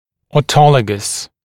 [ɔːˈtɔləgəs][о:ˈтолэгэс]аутогенный, аутологический, собственный